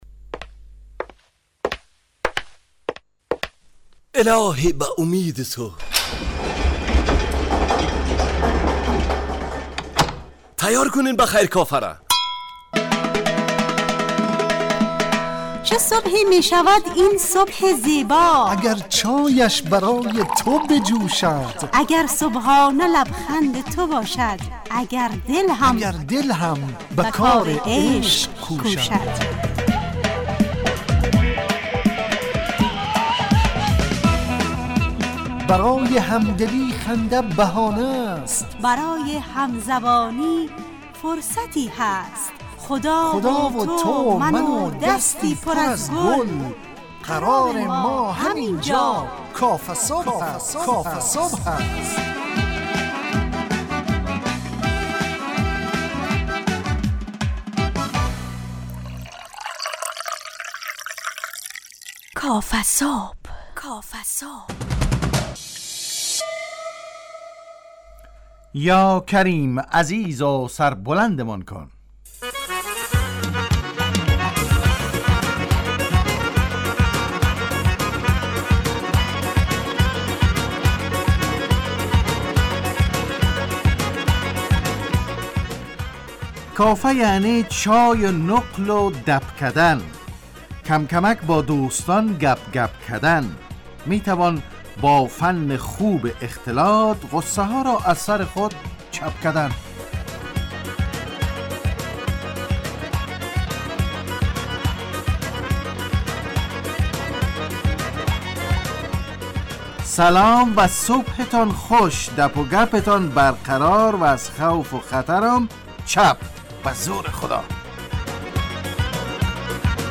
کافه صبح - مجله ی صبحگاهی رادیو دری با هدف ایجاد فضای شاد و پرنشاط صبحگاهی همراه با طرح موضوعات اجتماعی، فرهنگی، اقتصادی جامعه افغانستان همراه با بخش های کارشناسی، نگاهی به سایت ها، گزارش، هواشناسی و صبح جامعه، گپ صبح و صداها و پیام ها شنونده های عزیز